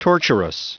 Prononciation du mot torturous en anglais (fichier audio)
Prononciation du mot : torturous